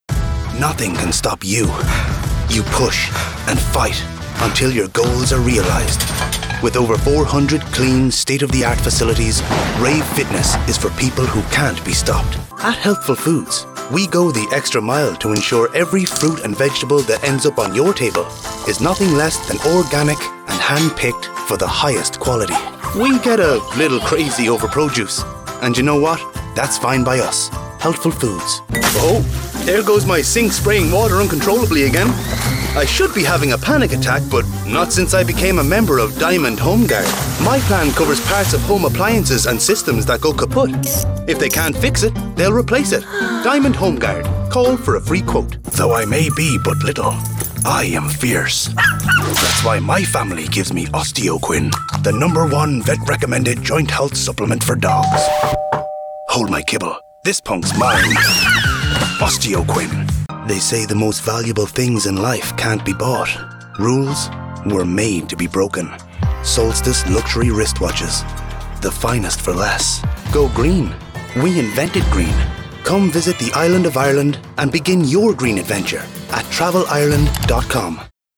Male
Neumann TLM 102 microphone, Focusrite 2i2 interface, Adobe Audition. Fully treated vocal booth.
20s/30s, 30s/40s
Irish Dublin Neutral